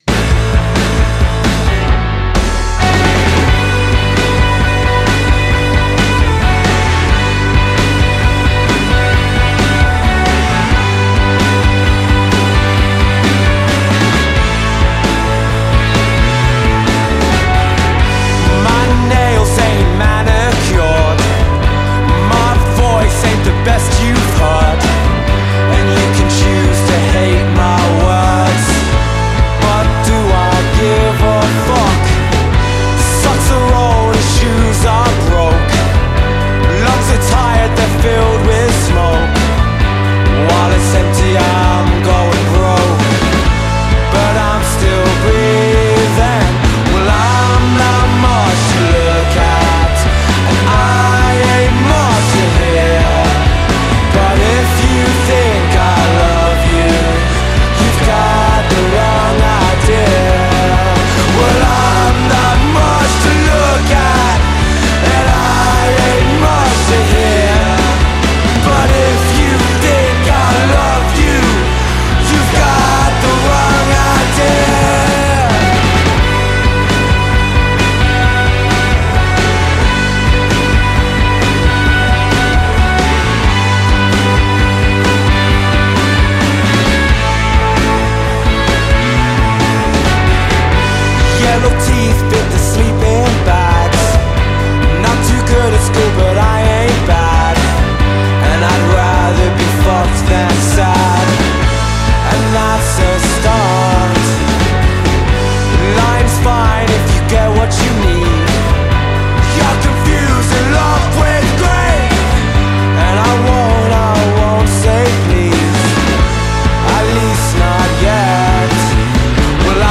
dringlichen post-punk in bester britischer manier
eine herrlich dahingerotzte hymne.